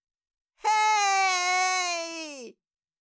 Cartoon Little Child, Voice, Shout, Scream, Long 4 Sound Effect Download | Gfx Sounds
Cartoon-little-child-voice-shout-scream-long-4.mp3